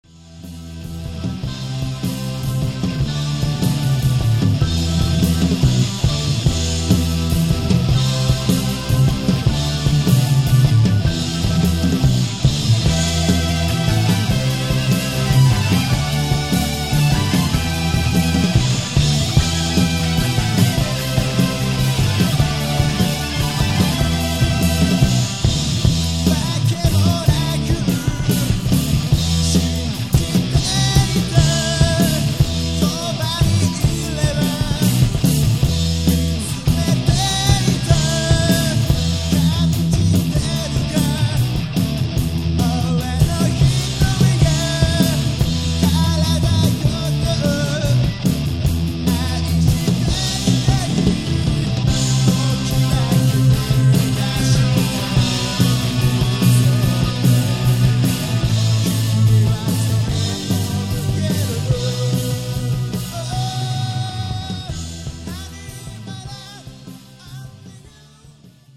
ミディアムテンポでメロディアスなサウンドを中心とした正統派ハードロックバンドである。
イントロでのギターとキーボードのユニゾンは実に素晴らしいメロディーを醸し出している。そしてボーカルも力強い。